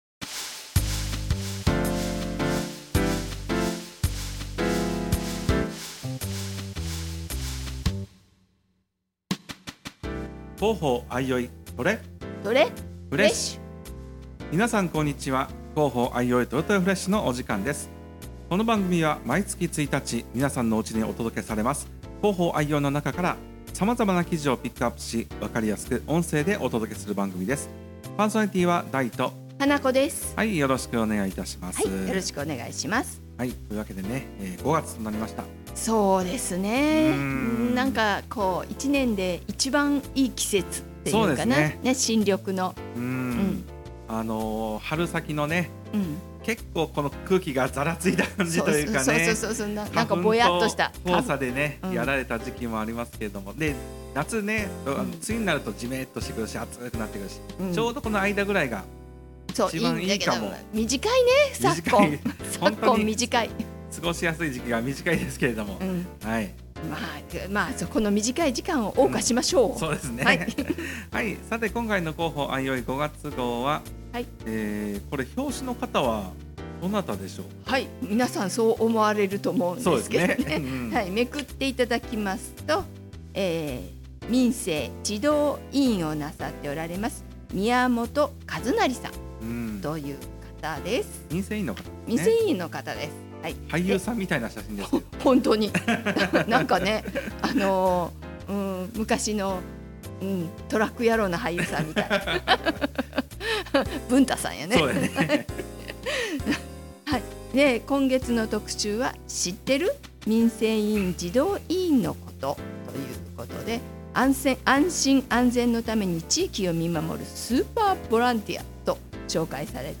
場所：相生市生きがい交流センター